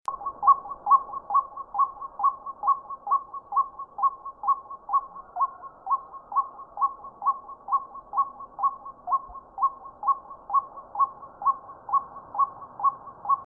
64-1-1金山2011白腹秧雞1.mp3
白腹秧雞 Amaurornis phoenicurus chinensis
新北市 金山區 金山
錄音環境 農田
清晨鳴叫